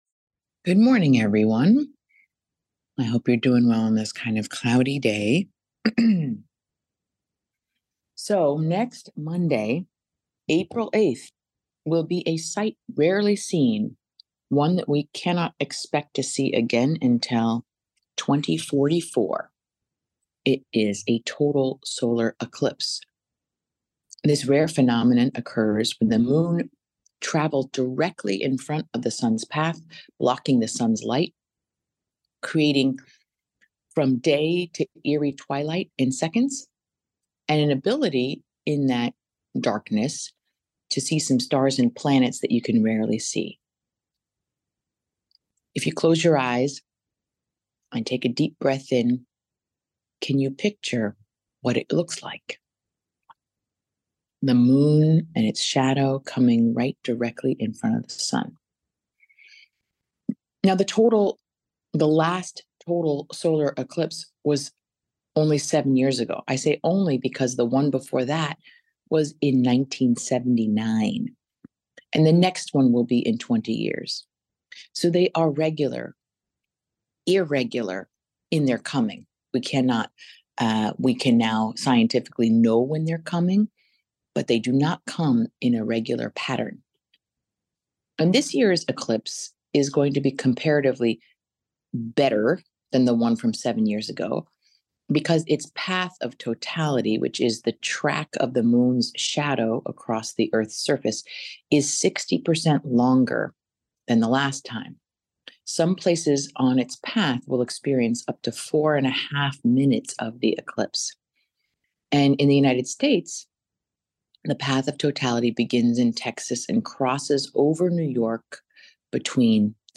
Weekly Meditation led by Rabbi Angela BuchdahlApril 30, 2024